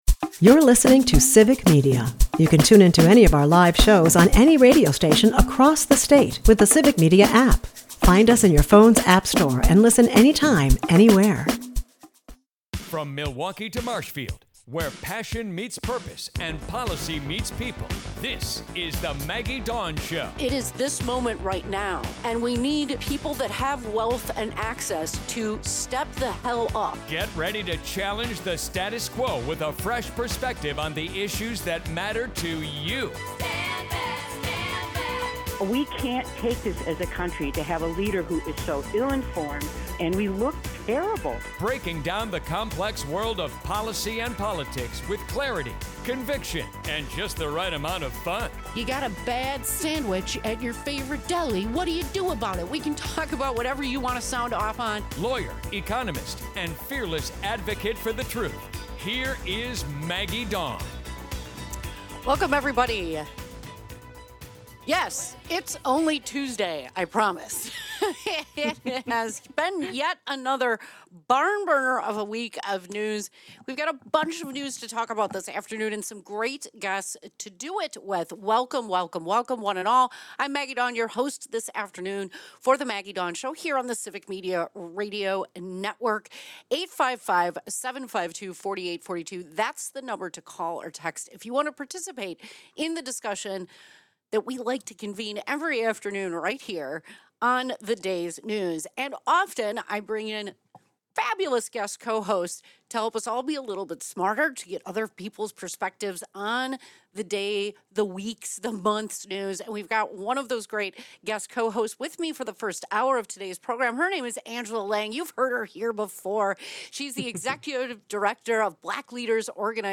We're building a state-wide radio network that broadcasts local news